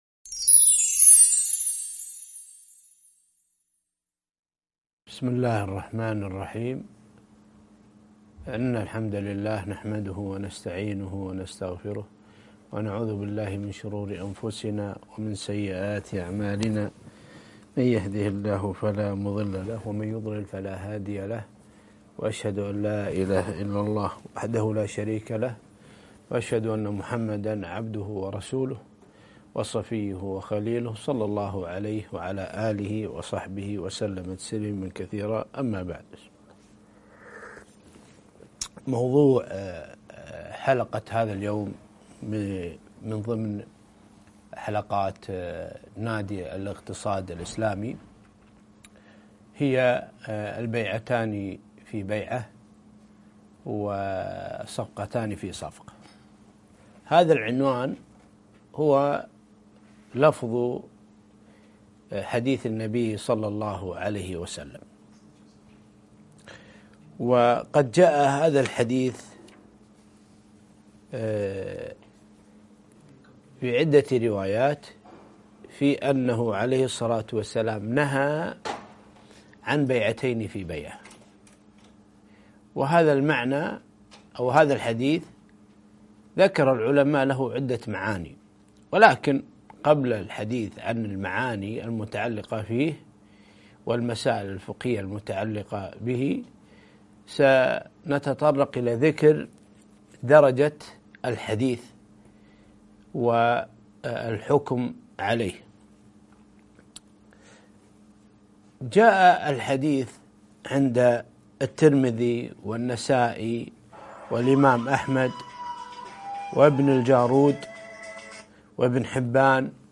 البيعتان في بيعة والصفقتان في صفقة - محاضرة